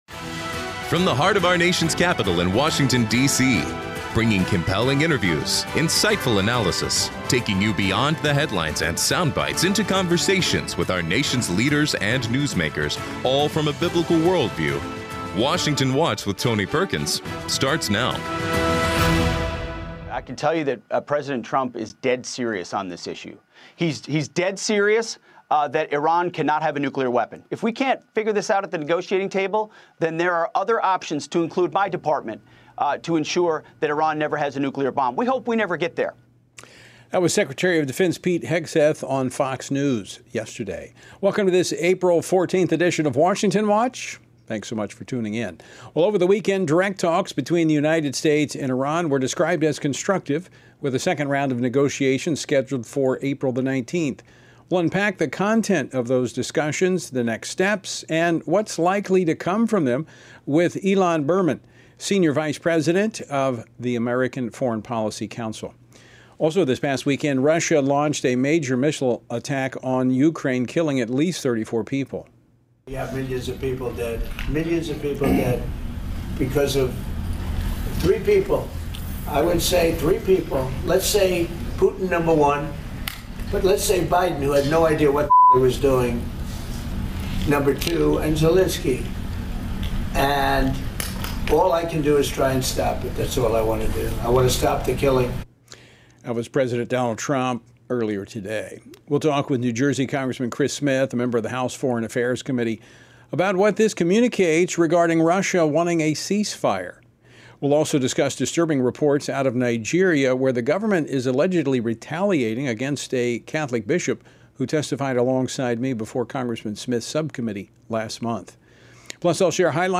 Hard hitting talk radio never has been and never will be supported by the main stream in America! Liberty News Radio is taking on the main stream press like never before!